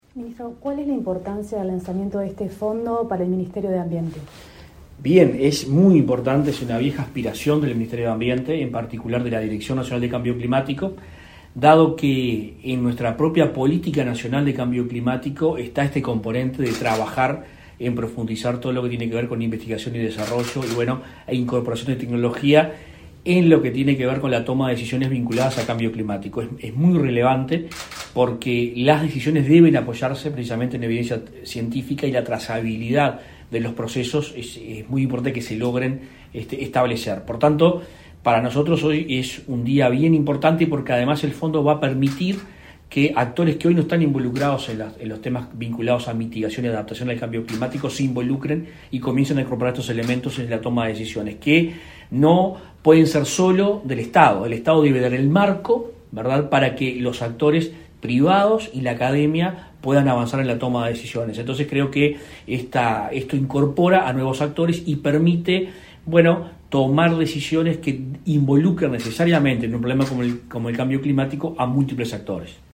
Entrevista al ministro de Ambiente, Adrián Peña
Entrevista al ministro de Ambiente, Adrián Peña 30/06/2022 Compartir Facebook X Copiar enlace WhatsApp LinkedIn Tras participar en el acto de lanzamiento del Fondo de Investigación e Innovación en Cambio Climático, impulsado por la Agencia Nacional de Investigación e Innovación (ANII) y BID Lab, este 30 de junio, el ministro Adrián Peña efectuó declaraciones a Comunicación Presidencial.